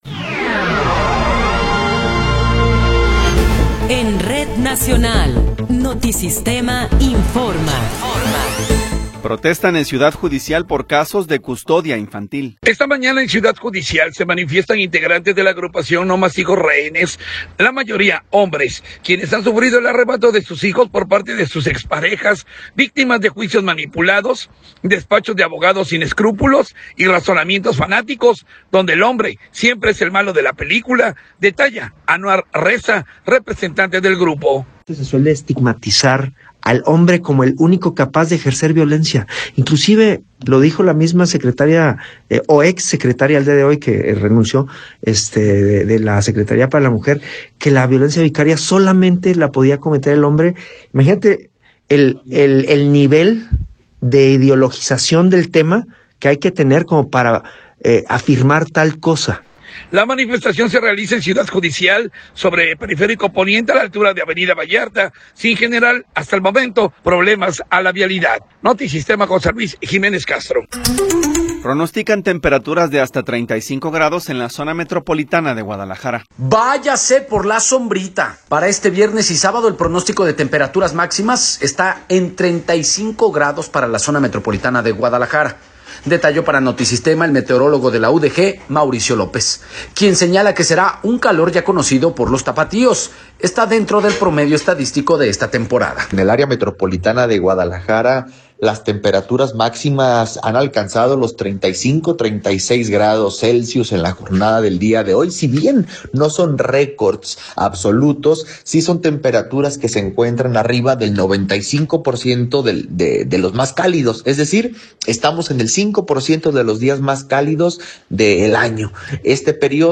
Noticiero 13 hrs. – 17 de Abril de 2026
Resumen informativo Notisistema, la mejor y más completa información cada hora en la hora.